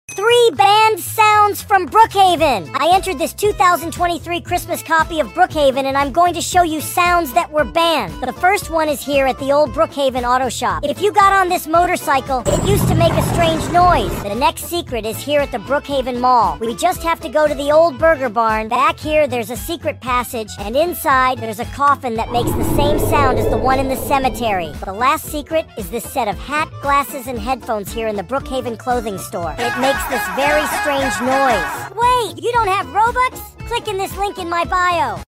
3 Scary Sounds Banned from sound effects free download